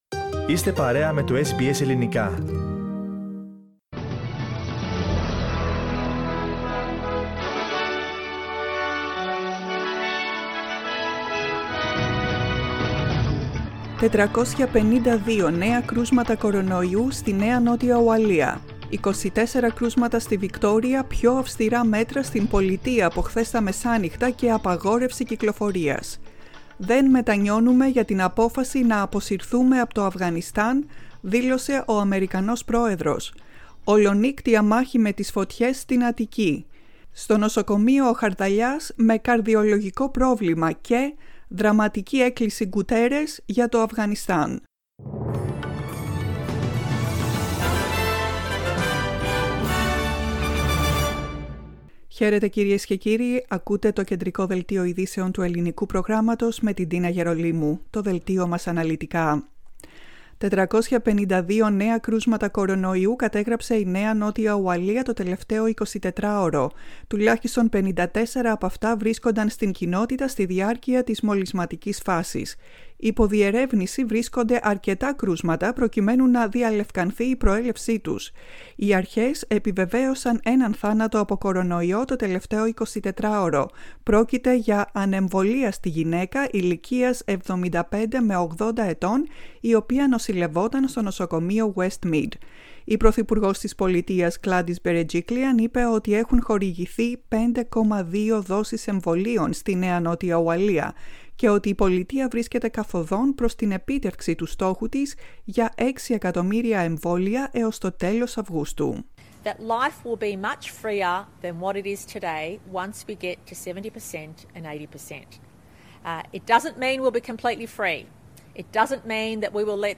The main bulletin of the day in Greek.